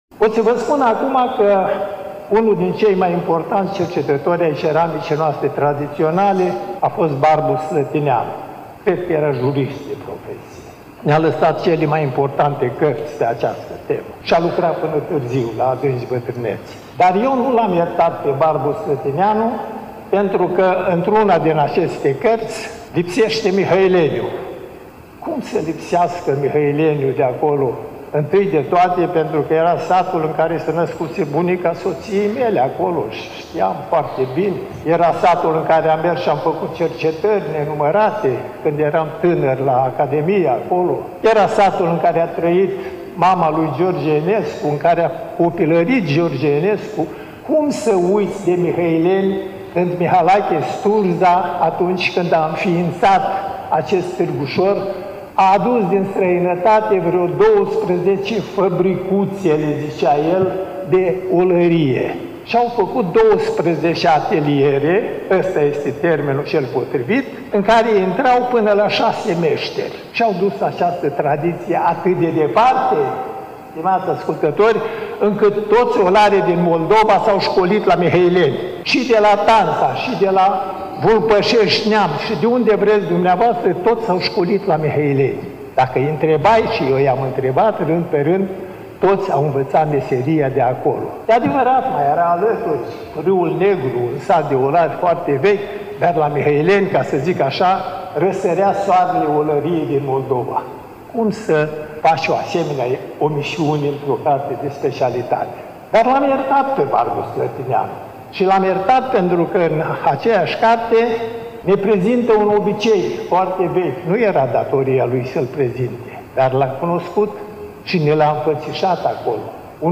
Cartea a fost lansată la Iași, nu demult, în Sala „Petru Caraman” din incinta Muzeului Etnografic al Moldovei, Palatul Culturii.